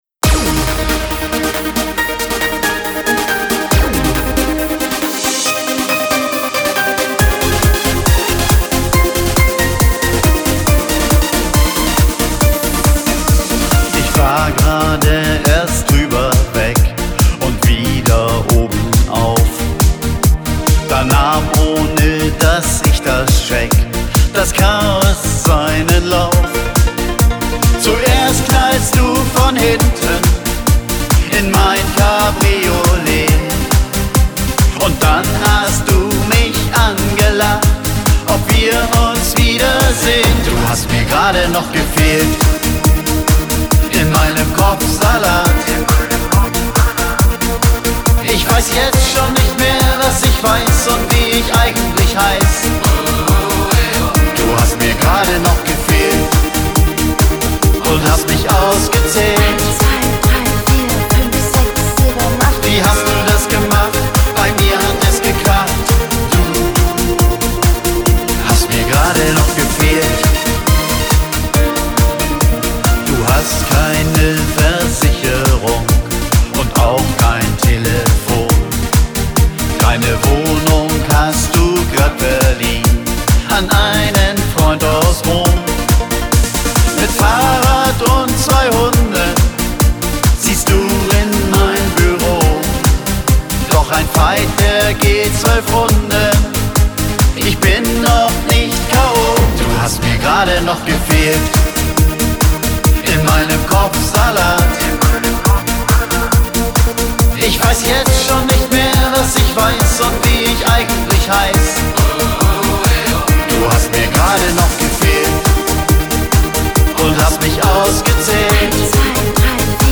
Der Gentleman des Popschlagers !